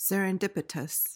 PRONUNCIATION:
(ser-uhn-DIP-i-tuhs)